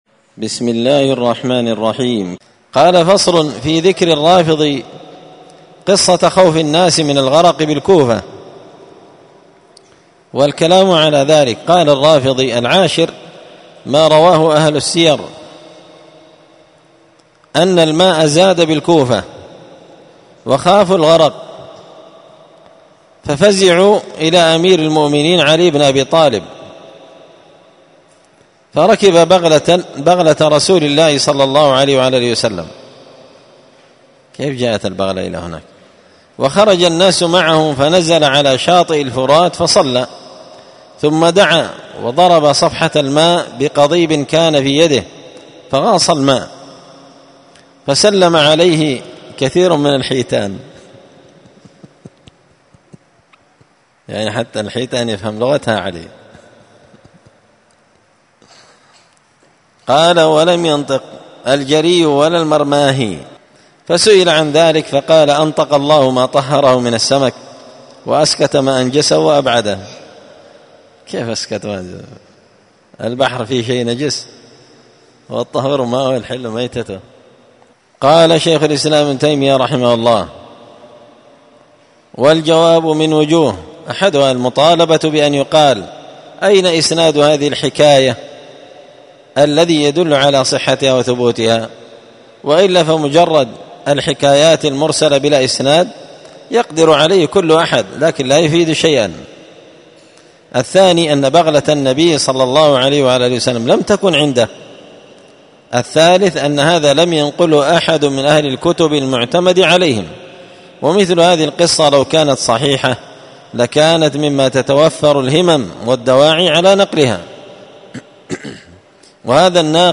الدرس العشرون بعد المائتين (220) فصل في ذكر الرافضي قصة خوف الناس من الغرق بالكوفة والكلام عليه
مسجد الفرقان قشن_المهرة_اليمن